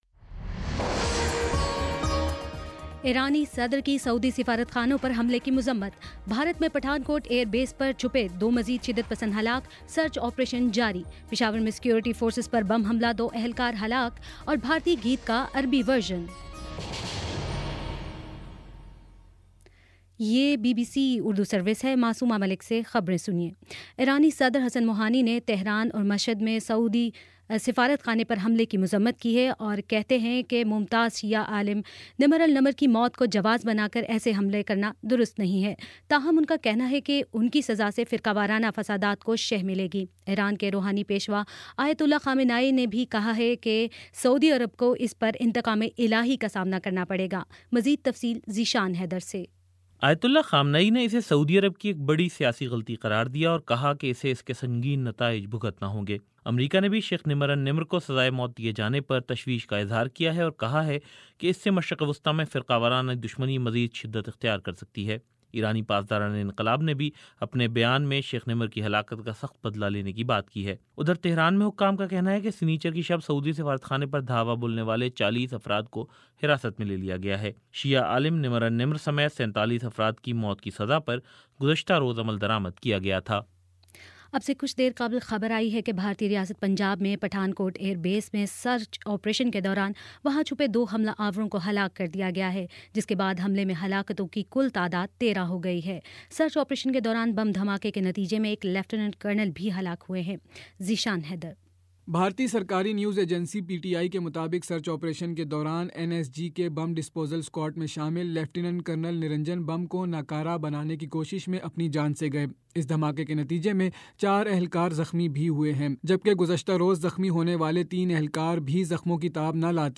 جنوری 03 : شام پانچ بجے کا نیوز بُلیٹن